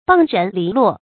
傍人篱落 bàng rén lí luò
傍人篱落发音
成语注音ㄅㄤˋ ㄖㄣˊ ㄌㄧˊ ㄌㄨㄛˋ